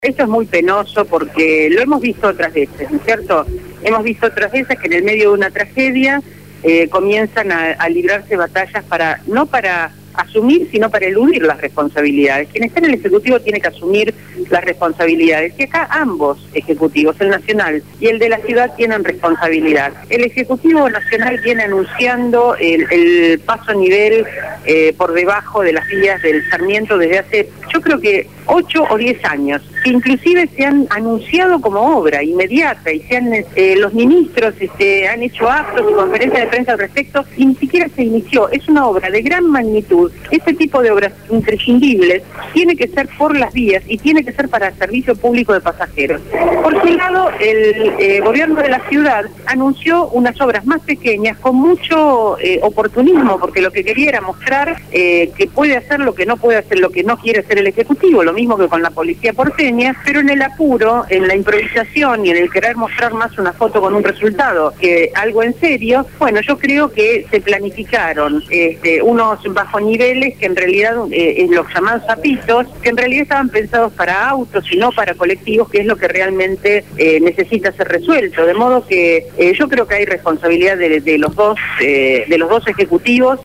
«La Coalición Cívica discute el hecho que haya ampliaciones de presupuesto y el endeudamiento de la Ciudad cuando no se ha ejecutado el presupuesto que la Ciudad ya tiene acordado para las obras» lo dijo en comunicacion telefonica la Diputada de la Ciudad Autónoma de Buenos Aires Diana Maffía